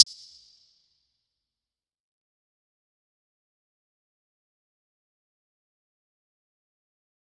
DMV3_Hi Hat 2.wav